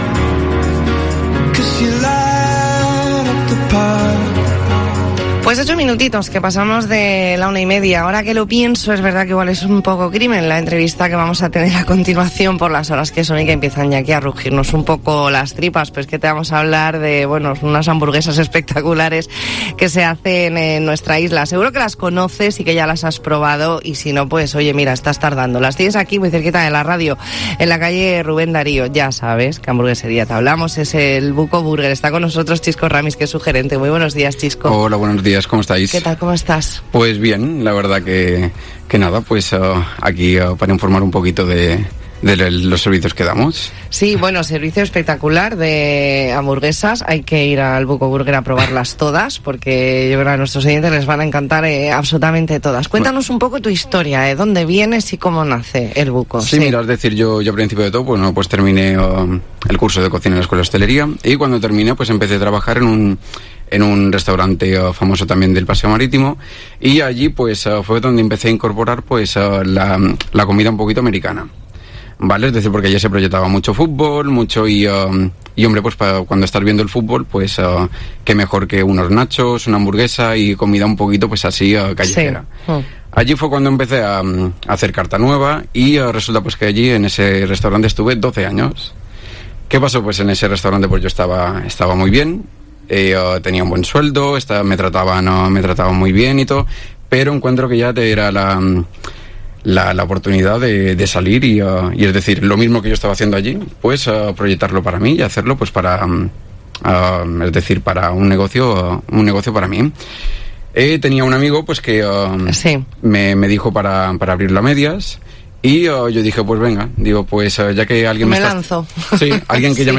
E ntrevista en La Mañana en COPE Más Mallorca, lunes 23 de octubre de 2023.